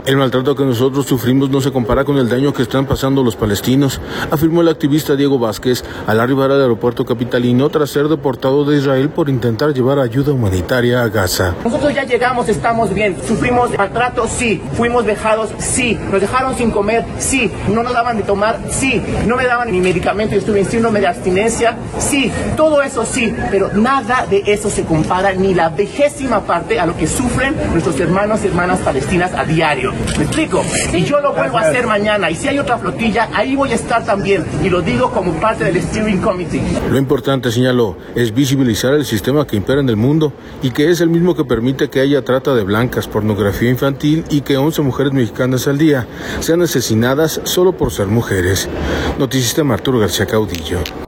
al arribar al aeropuerto capitalino, tras ser deportado de Israel por intentar llevar ayuda humanitaria a Gaza.